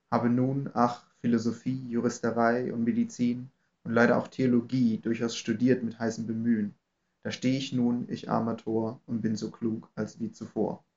Die Aufnahmen wurden in verschiedenen Abständen aufgenommen.
Ein Hinweis: Die Aufnahmen wurden in einem akustisch gut ausgestatteten Videokonferenzraum aufgenommen.
Abstand 100 cm - Mikrofon vom Sprecher abgewandt
100cm abgewandt als Originalaufnahme © vcc